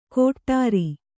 ♪ kōṭṭāri